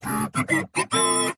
transforms
变形